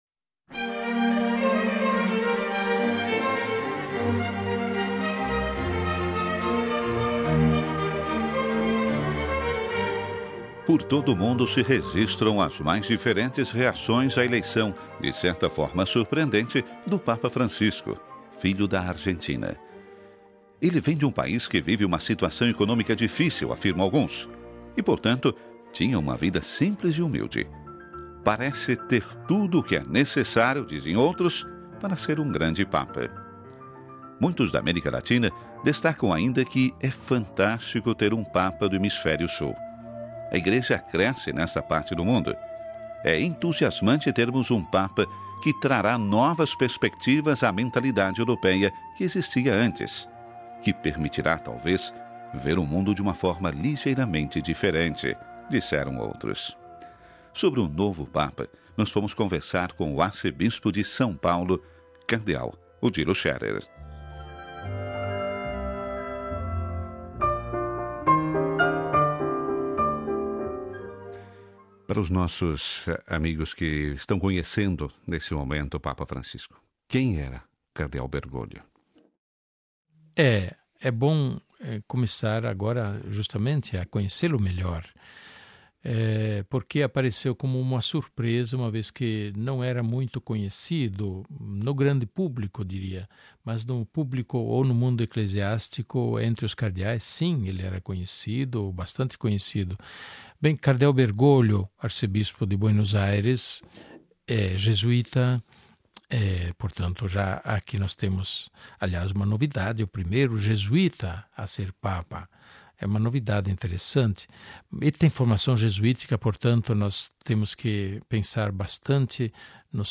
Sobre o novo Papa nós fomos conversar com o Arcebispo de São Paulo, Cardeal Odilo Scherer.